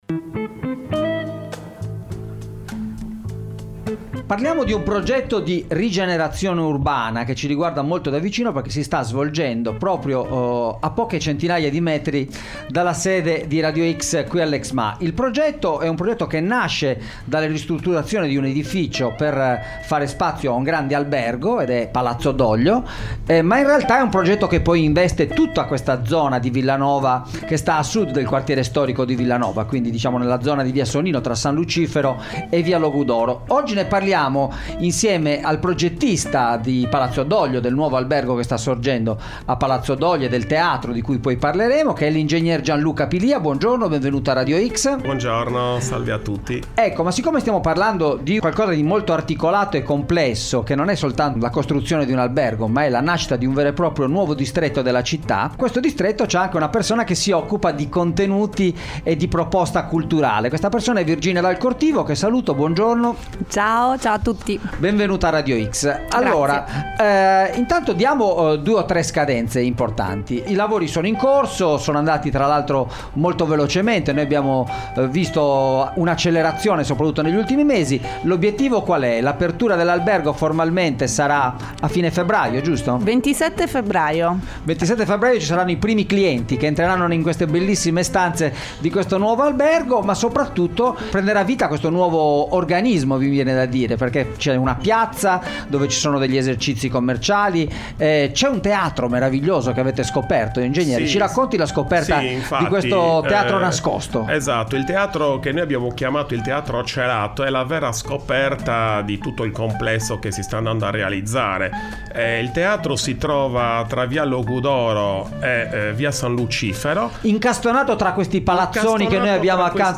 interviste_palazzodoglio0302.mp3